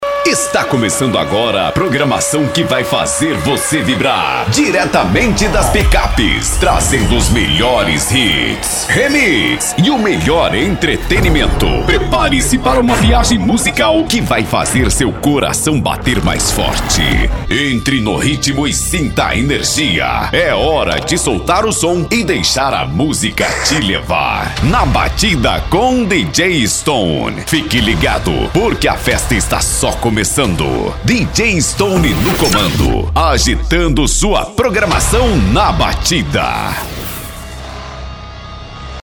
Precisa daquele IMPACTO de qualidade ?